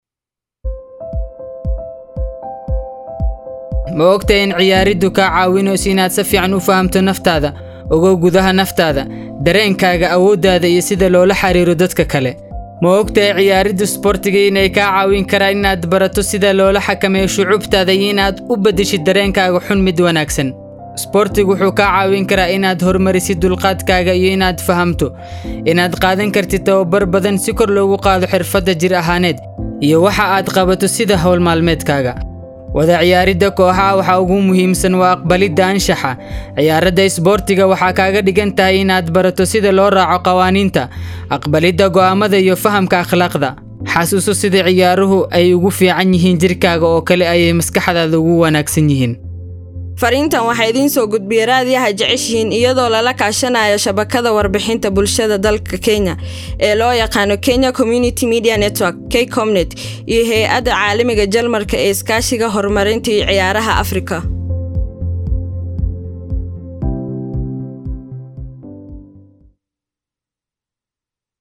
What role does sports play in our society and how do women take part in sports -Somali Radio spot done by KCOMNET in conjunction with Sports for Development Africa.
SOMALI-RADIO-SPOTS-1.mp3